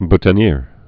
(btə-nîr, -tən-yâr)